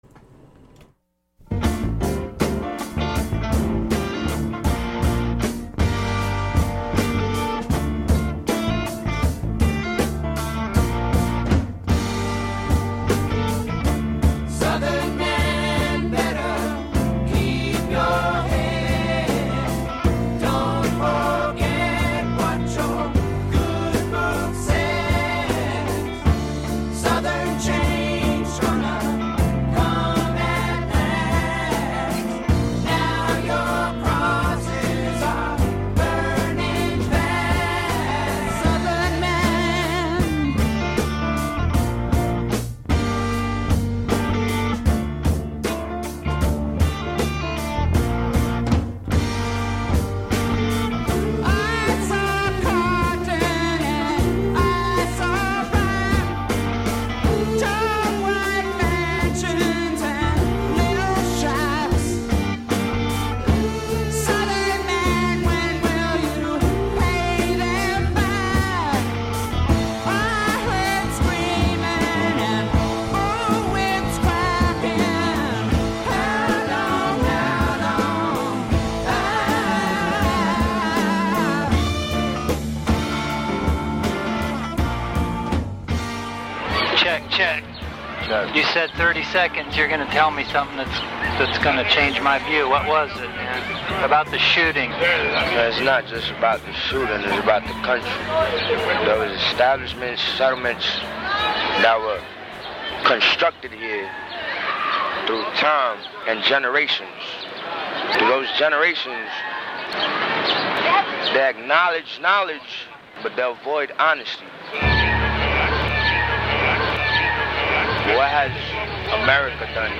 Set: Interview with a Young Homeless Man in Union Square PArk